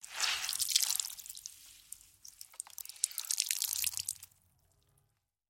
Звуки слёз
Звук віджимання мокрої хустки від сліз